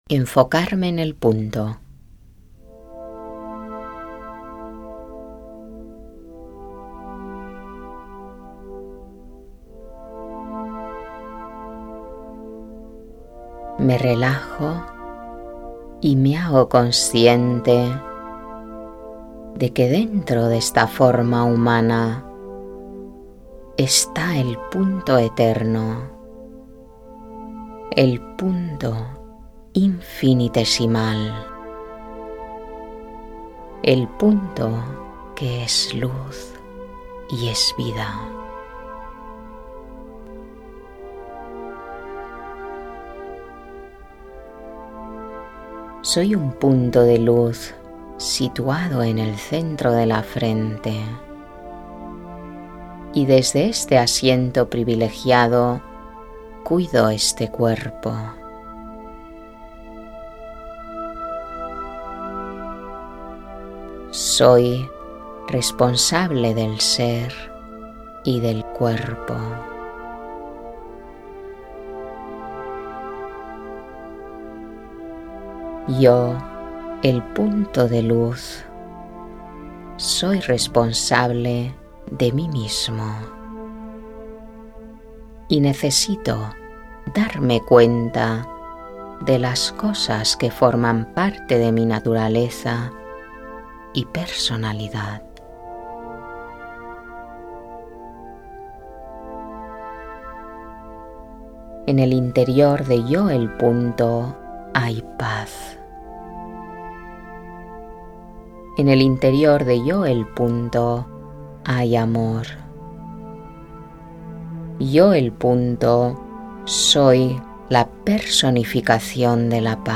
meditaciones-guiadas